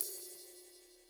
Crashes & Cymbals
Ride Groovin 2.wav